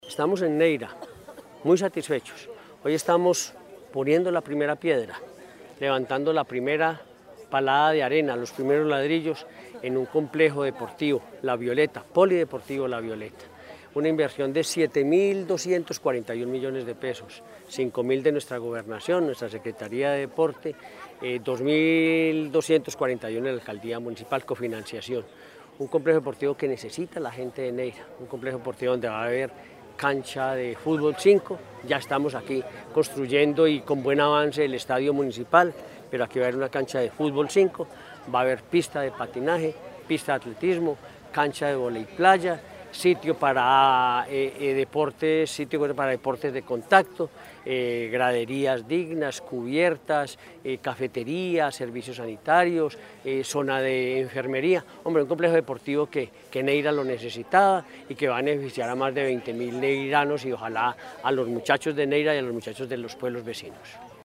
Henry Gutiérrez Ángel, gobernador de Caldas.
Henry-Gutierrez-Angel-gobernador-de-Caldas.mp3